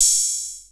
BIG PERC (24).wav